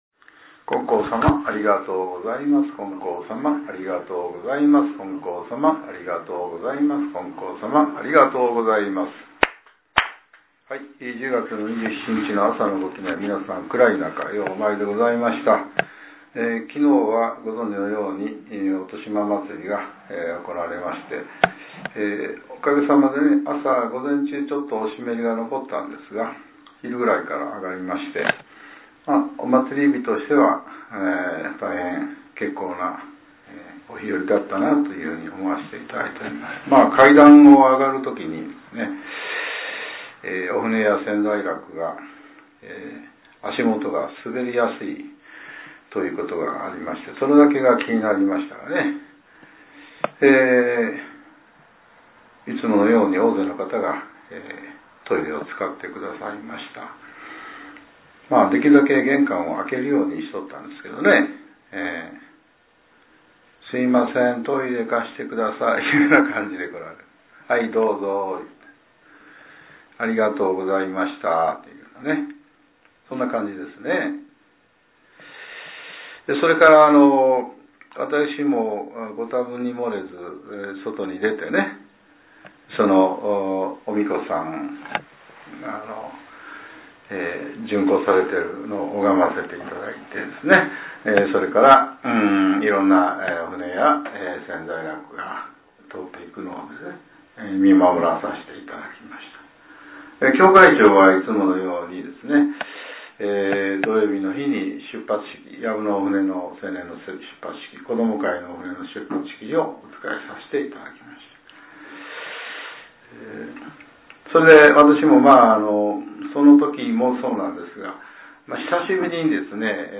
令和７年１０月２７日（朝）のお話が、音声ブログとして更新させれています。